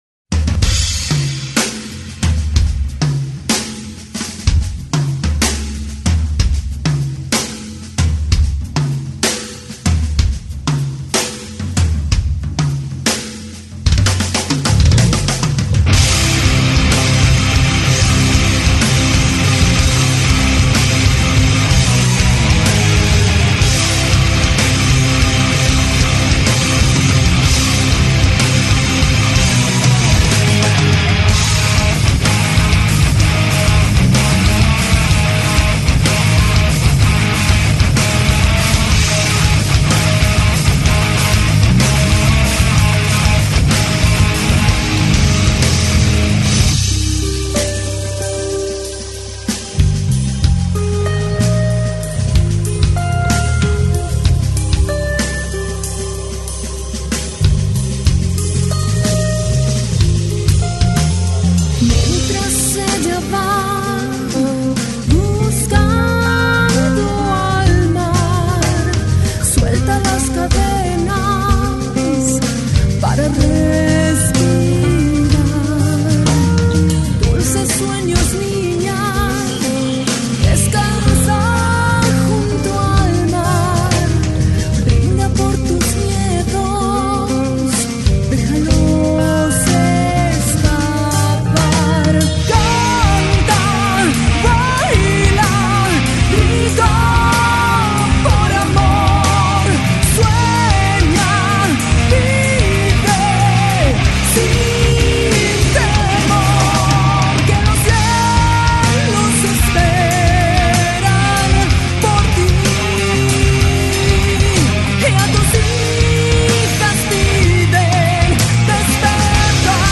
Entrevista a Crisálida | FLACSO Radio